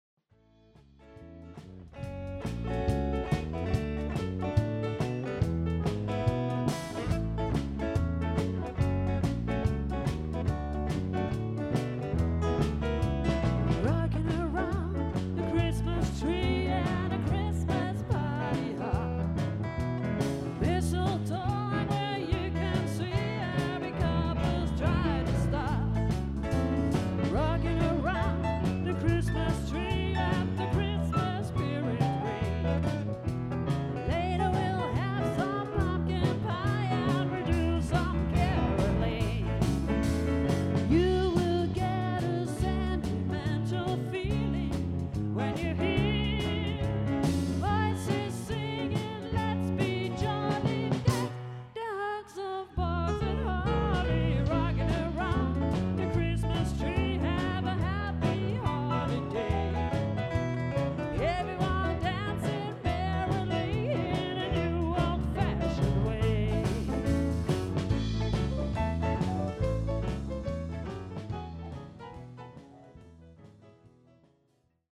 Auf dieser Seite findet Ihr Hörproben unserer Auftritte.